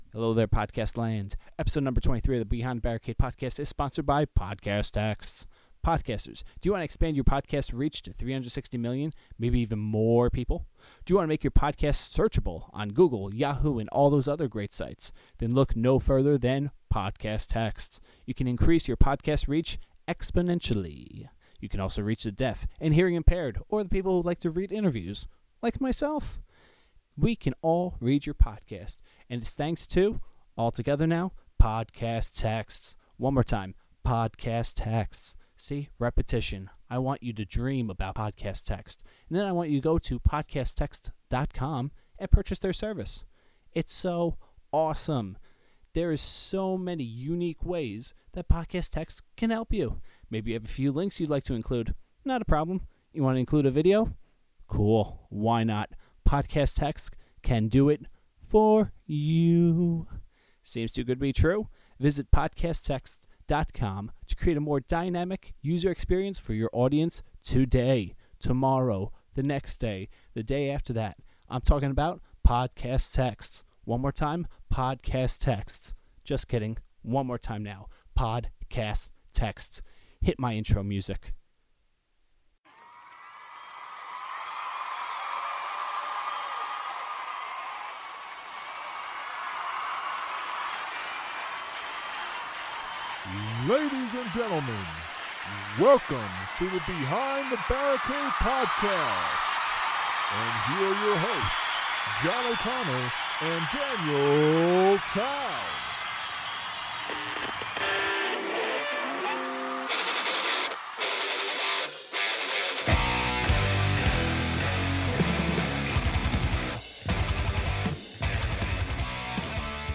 Pre-Interview Song: Magma Post-Interview Song: New Wave